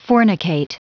Prononciation du mot fornicate en anglais (fichier audio)
Prononciation du mot : fornicate